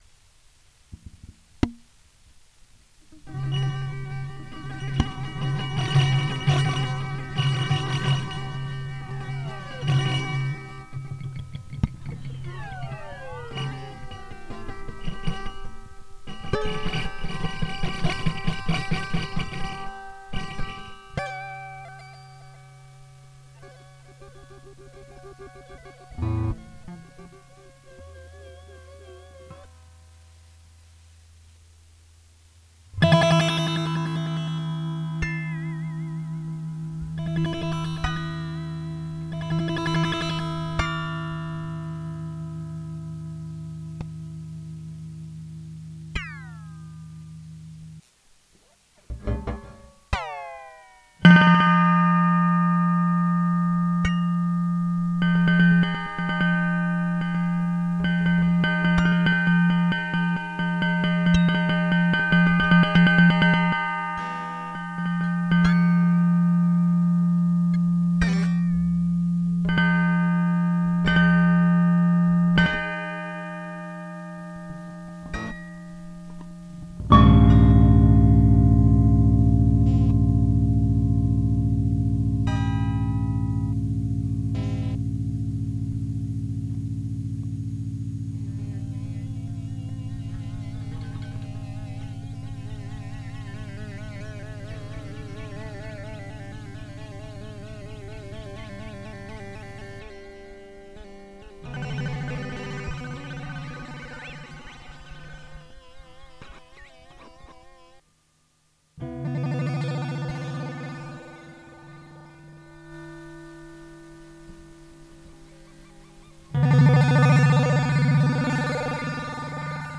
Voici le résultat des premiers tests, des sons  naturels de Télécaster, des sons glissés par l’action  d’un bottleneck sur les cordes, et le rebond d’une petite pince électrique crocodile.